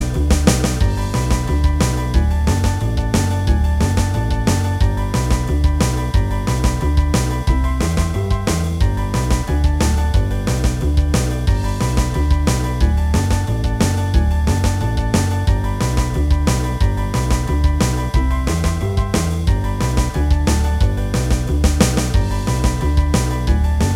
Minus Guitars Pop (1960s) 2:04 Buy £1.50